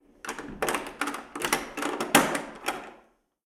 Llave en una puerta
cerradura
Sonidos: Hogar